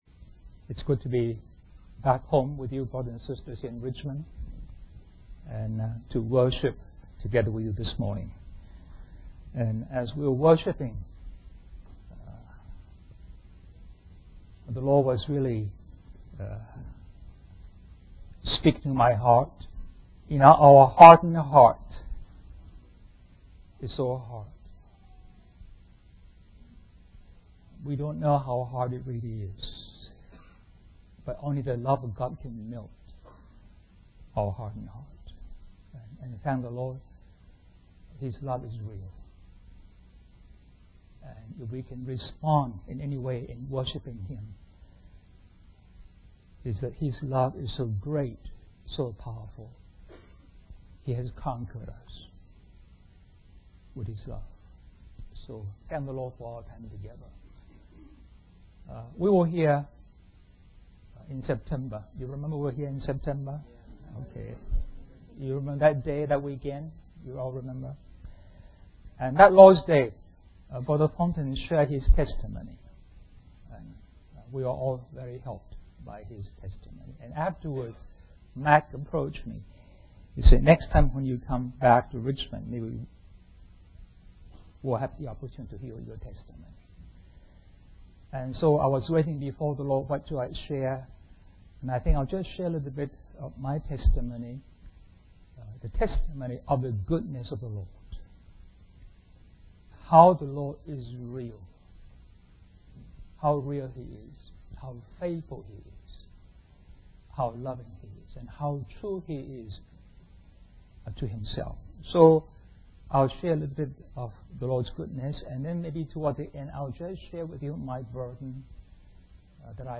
In this sermon, the speaker shares a testimony of the goodness of the Lord and how real, faithful, loving, and true He is.